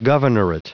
Prononciation du mot governorate en anglais (fichier audio)
Prononciation du mot : governorate